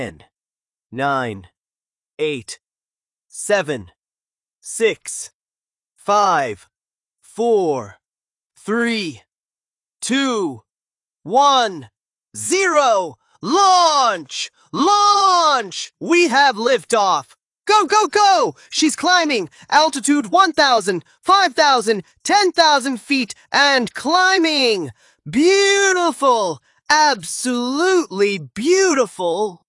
如上面这段提示词，模型感知到文本的节奏逐渐加快，从倒计时阶段的专业、冷静到最后情绪爬升与赞叹时，还原出了人物的情绪变化。